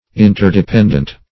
\In`ter*de*pend"ent\